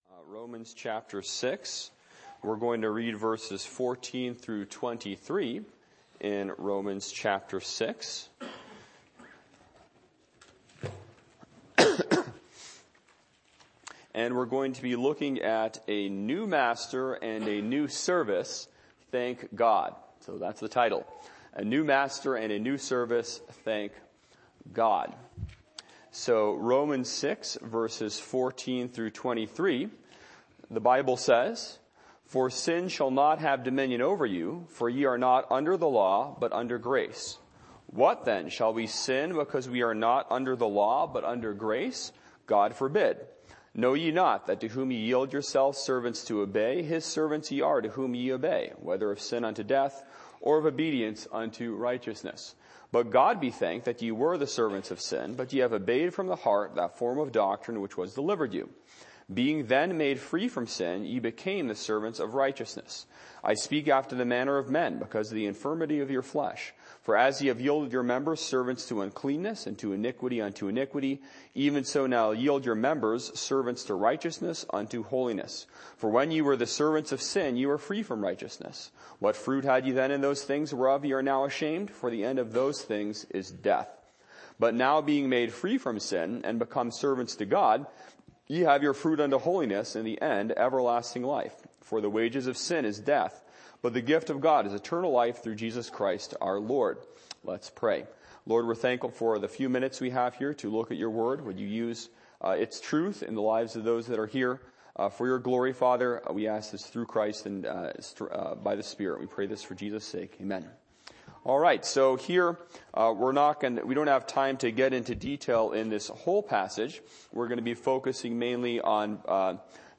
Passage: Romans 6:1-22 Service Type: Thanksgiving %todo_render% « The Outward Expression